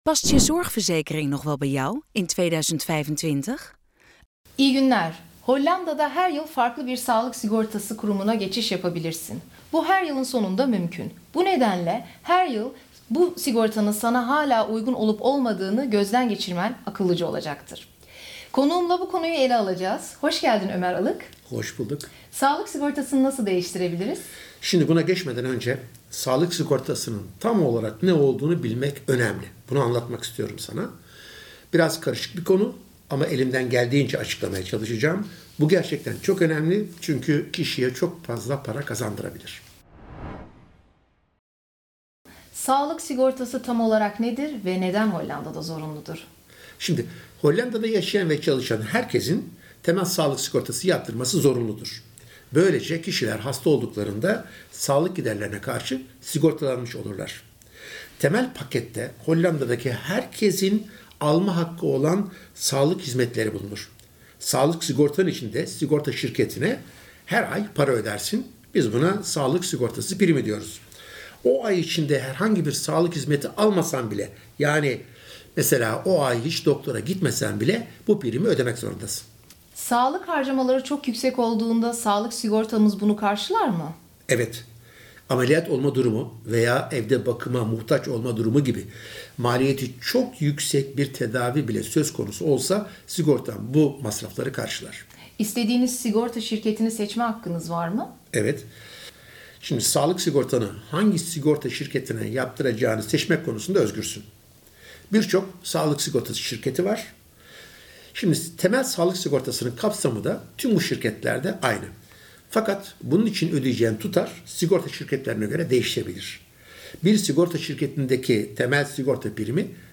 Bir kişi diğerine anlatıyor (konuşma).